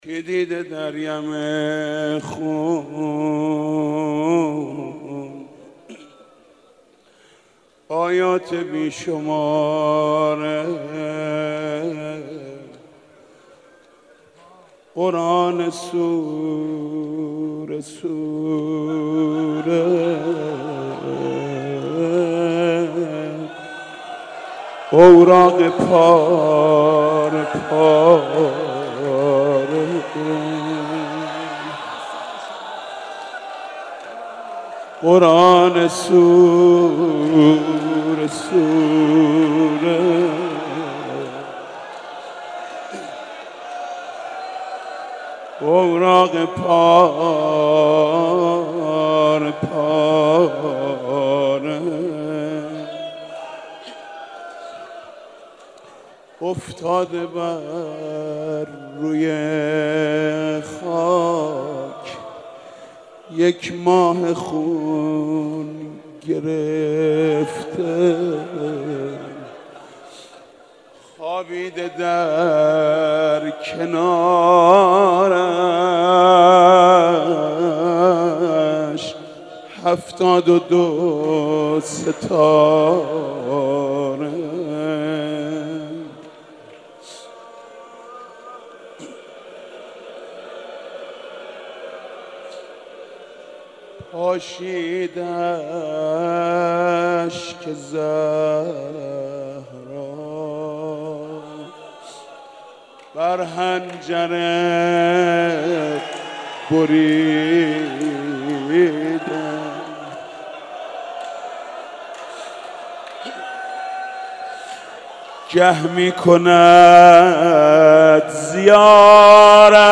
عقیق: مراسم شب یازدهم ماه مبارک رمضان با سخنرانی حجت الاسلام و المسلمین سید مهدی میرباقری، مناجات خوانی و قرائت دعای افتتاح توسط حاج منصور ارضی و مداحی حاج محمود کریمی در مسجد ارک برگزار شد. در ادامه صوت کامل مداحی حاج محمود کریمی در یازدهمین شب مناجات در مسجد ارک را بشنوید: دریافت